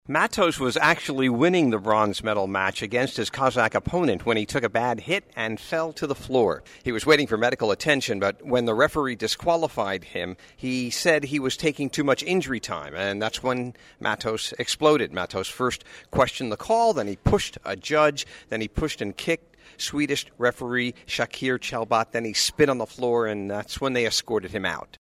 2008 Beijing Olympics, China, Fox News Radio, Selected Reports: